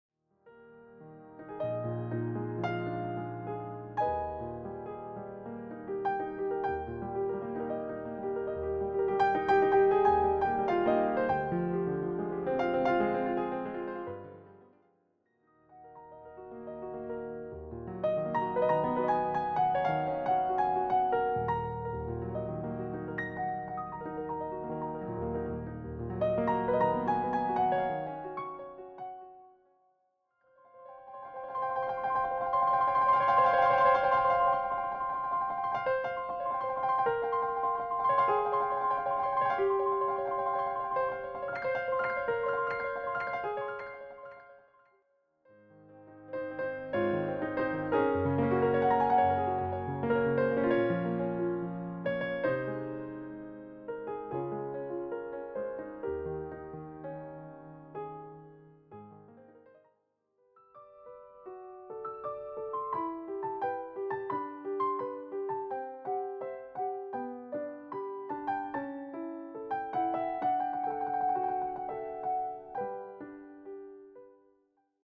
presented as relaxed piano interpretations.